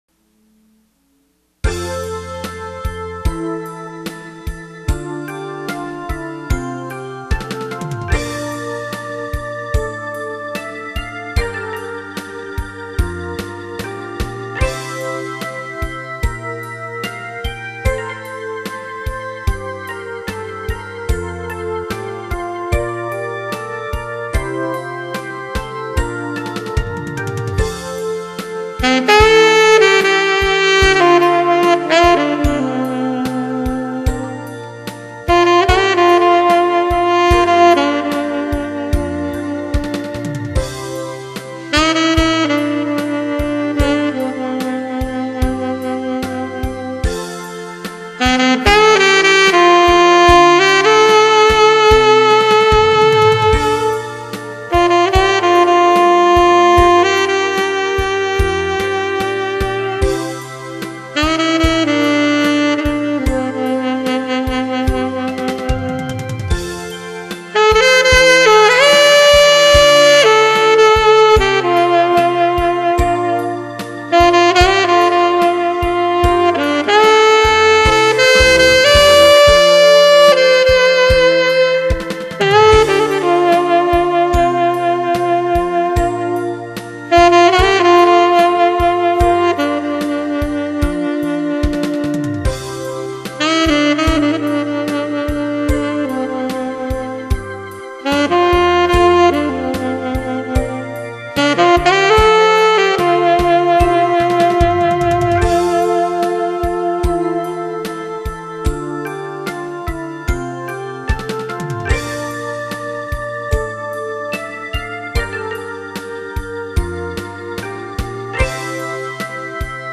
올드팝